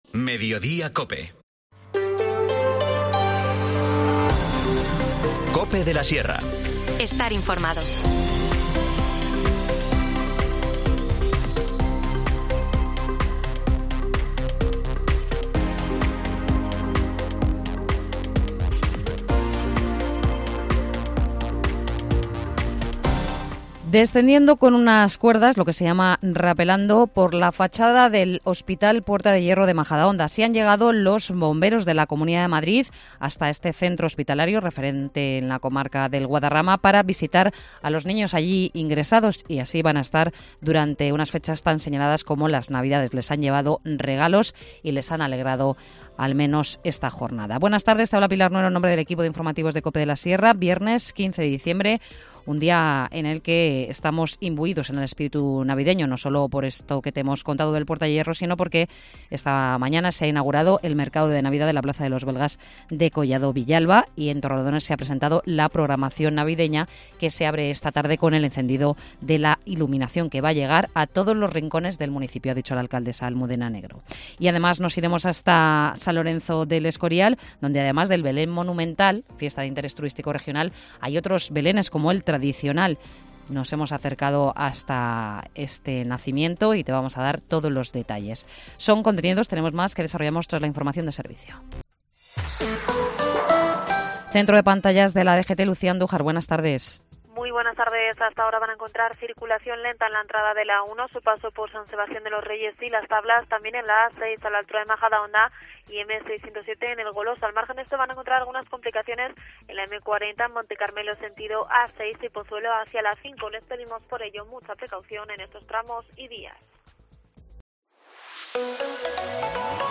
Informativo | Mediodía en Cope de la Sierra, 15 de diciembre de 2023